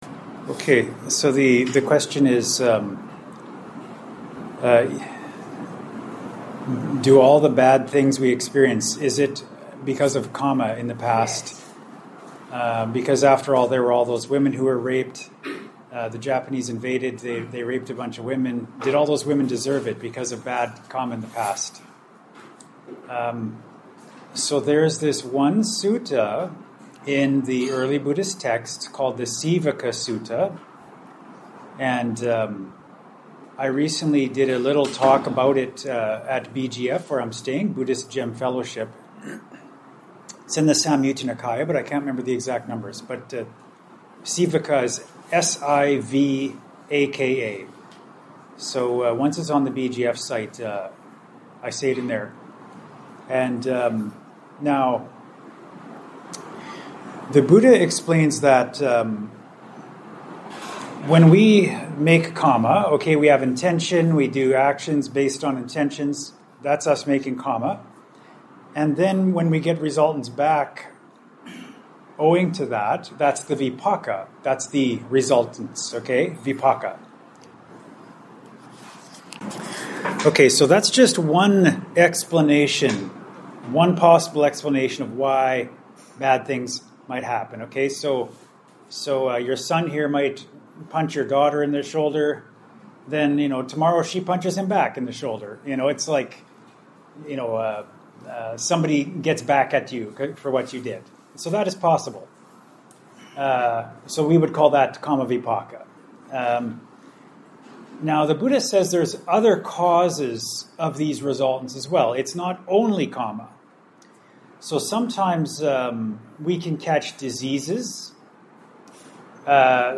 Recorded at a Dana meal in Kuala Lumpur, Malaysia, given to an audience of about 20, here are the audio-only recordings (Tip: tap and hold to “Download link”, or right click to “Save Link As…“).